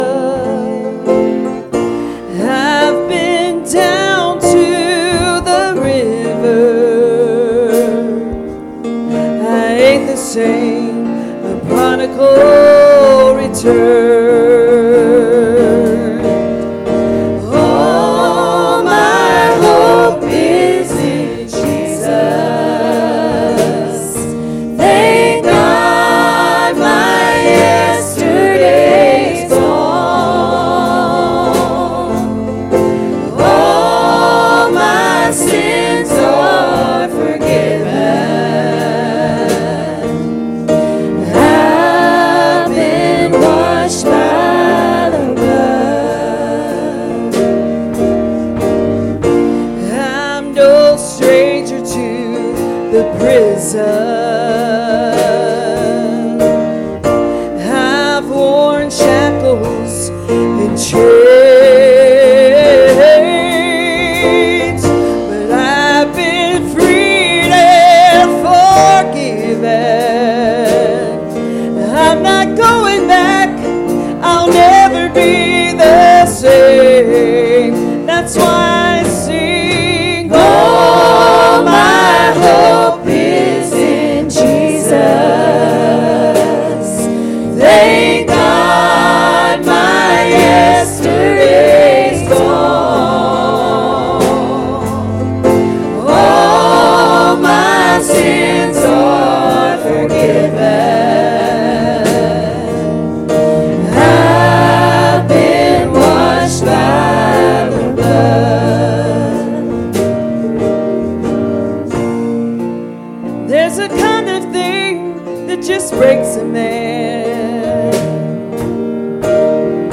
Service Type: Sunday Morning Services